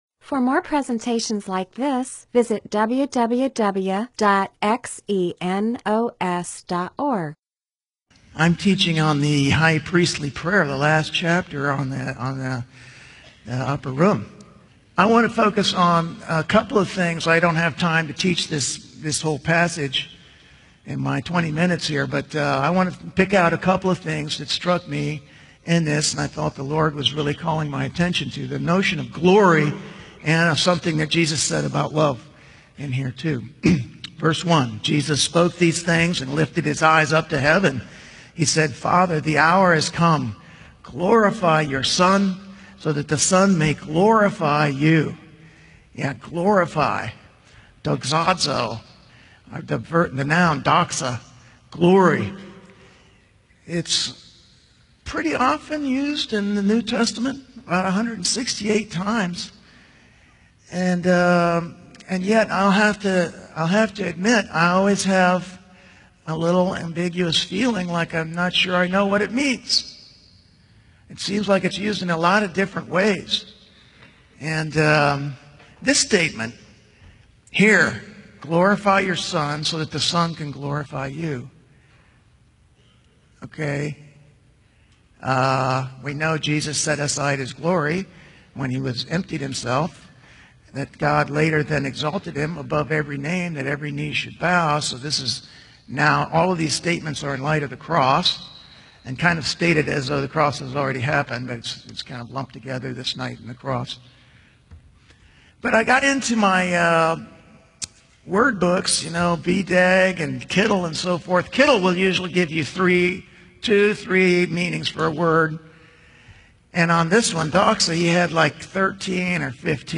MP4/M4A audio recording of a Bible teaching/sermon/presentation about John 17:1-26.